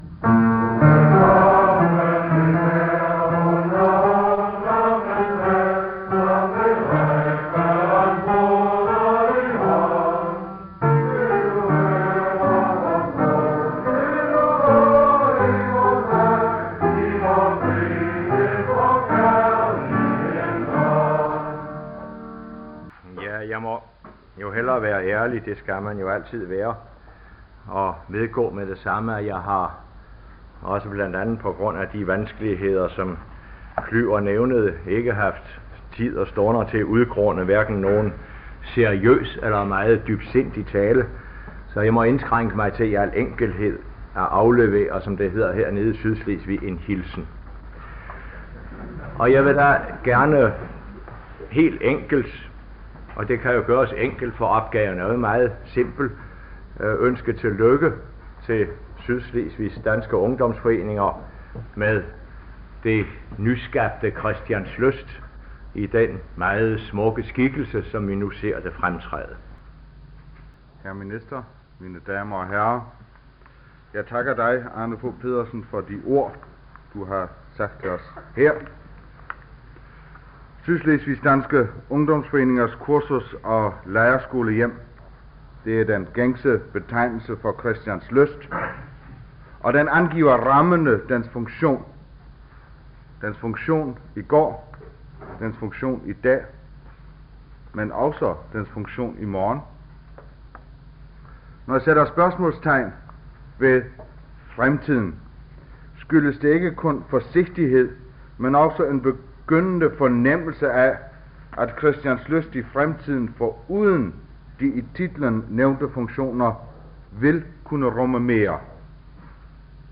Uddrag af indvielsen af Christianslysts tilbygninger 1970.